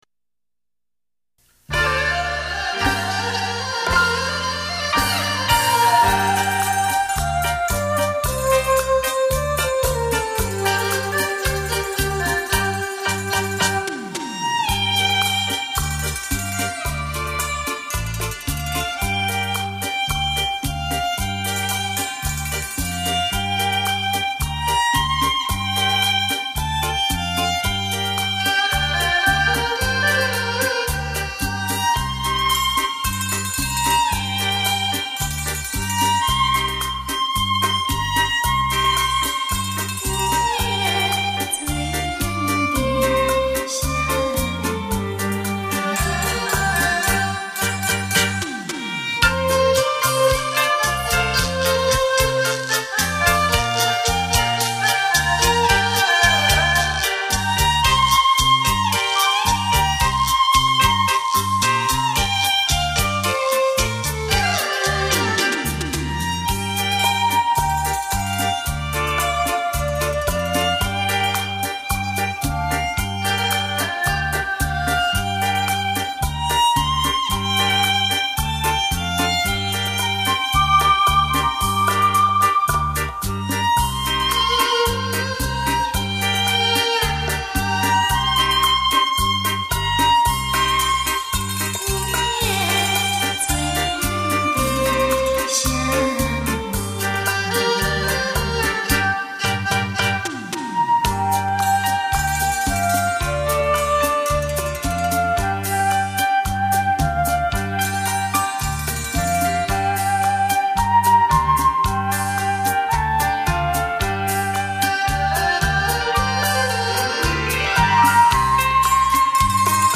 （怀旧老曲 -- 民乐和西乐的结合）